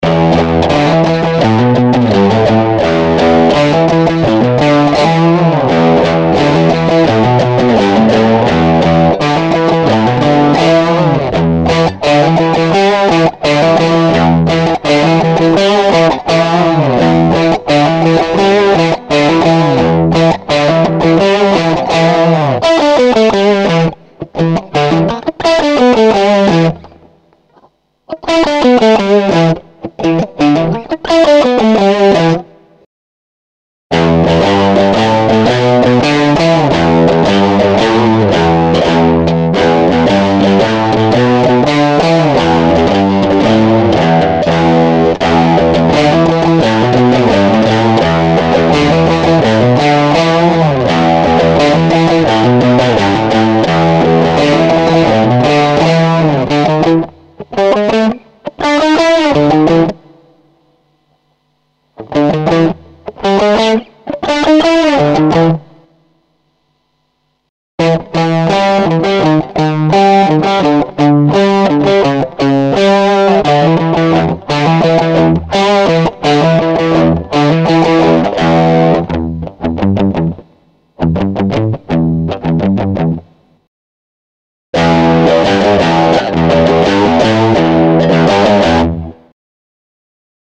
47 Loop Packs: A diverse array of loops covering various rock genres, from classic rock and hard rock to modern alternative and punk.
Versatile Tempos: Ranging from laid-back grooves to adrenaline-pumping speeds, ensuring you find the perfect match for your project’s tempo.
Premium Quality: Professionally recorded and mixed to deliver pristine sound quality that stands out in any mix.
Authentic Rock Sound: Capture the essence of rock with guitar loops that feature crunchy power chords, soaring solos, and intricate arpeggios.